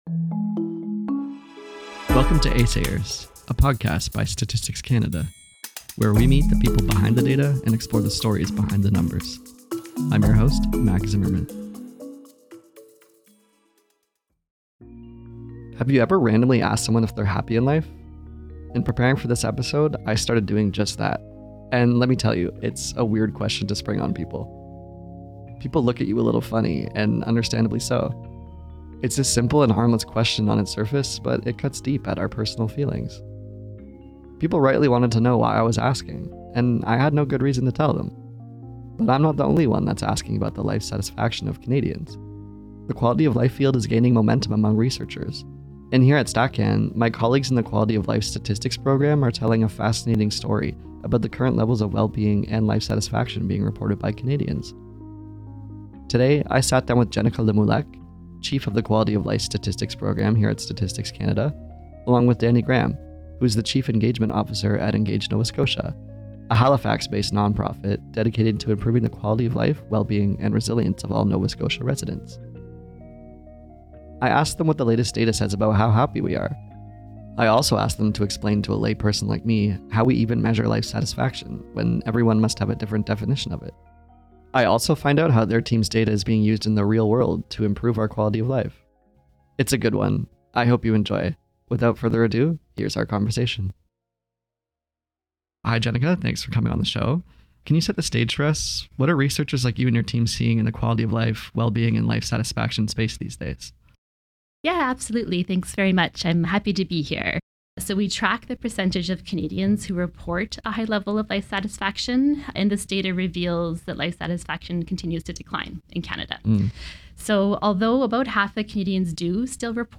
Without further ado, here's our conversation.